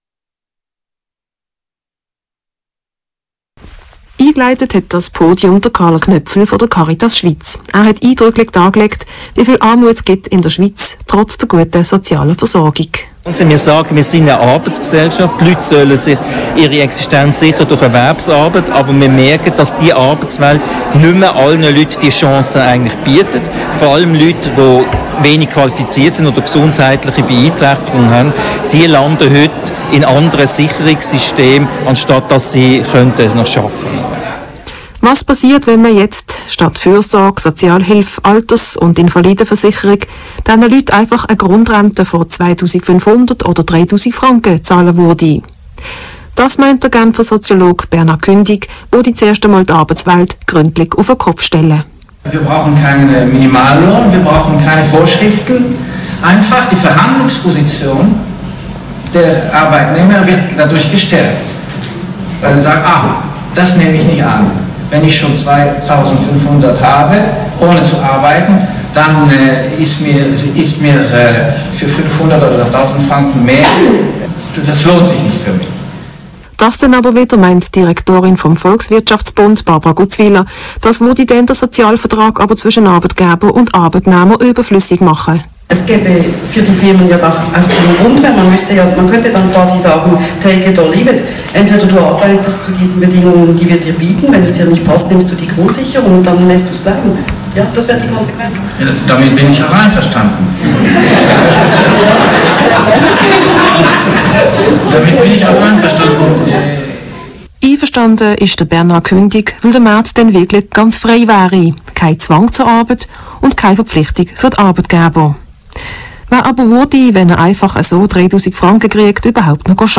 Ausschnitt aus dem Regionaljournal